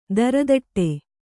♪ daradaṭṭe